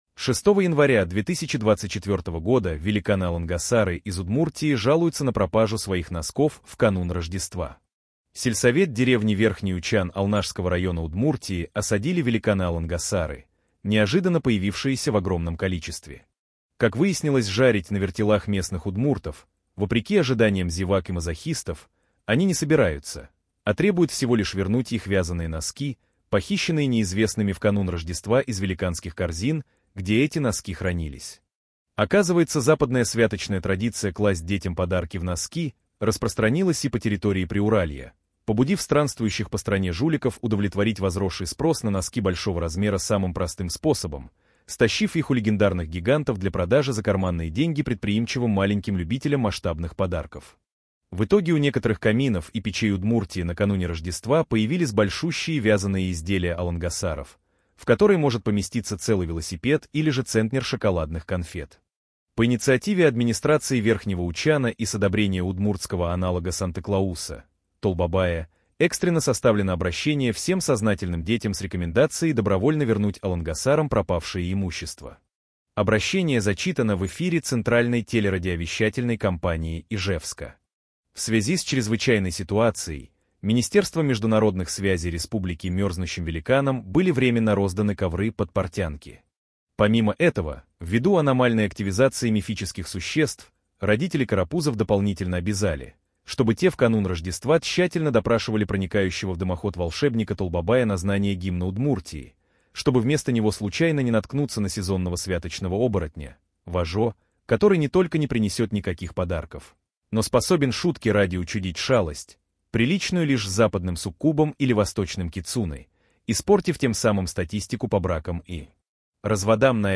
Голос «Герман», синтез речи нейросетью